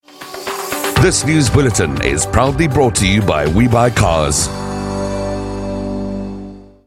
Professional voiceover work for commercials, promos, podcasts, and more.
We_Buy_Cars_News_Sponsor_-_English.mp3